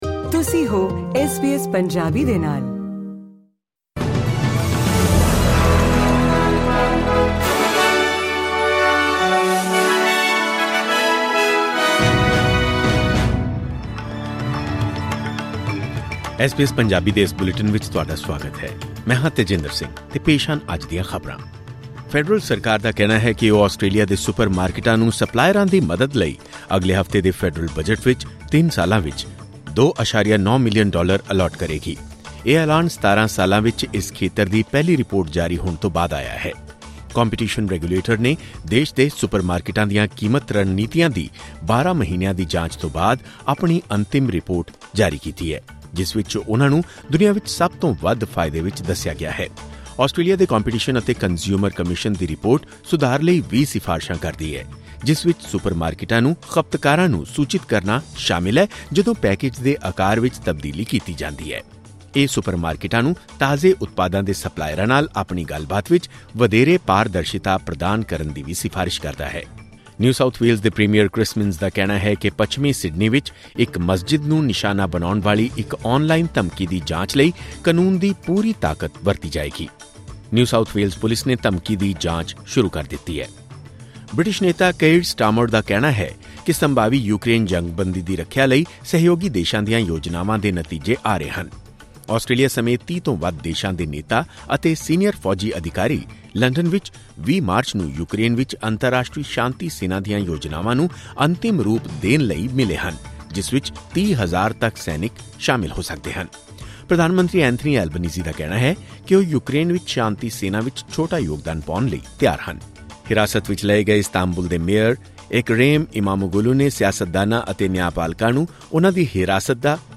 ਖਬਰਨਾਮਾ: ਆਸਟ੍ਰੇਲੀਆਈ ਸੁਪਰਮਾਰਕੀਟਾਂ ਹਨ ਦੁਨੀਆ ਦੀਆਂ ਸਭ ਤੋਂ ਵੱਧ ਲਾਭਕਾਰੀ ਸੁਪਰਮਾਰਕੀਟਾਂ ਵਿੱਚੋਂ ਇੱਕ